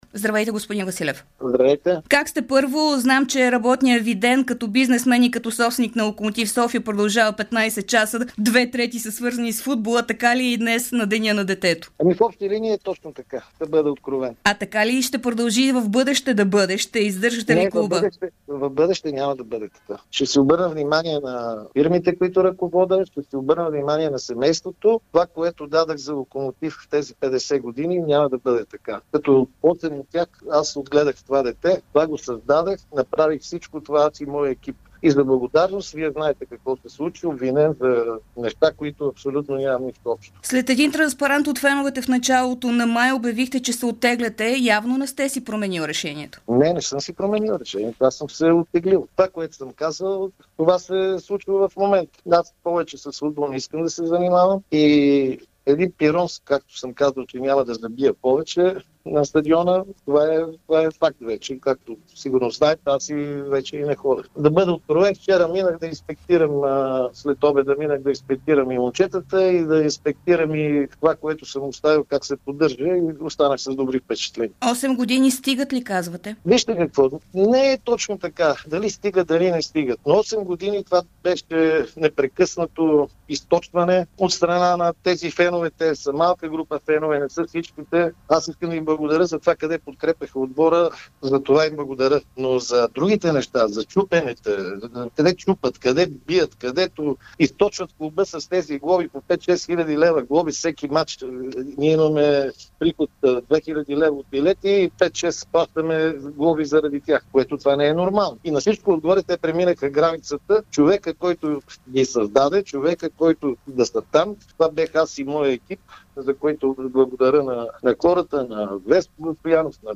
говори в ефира на Дарик радио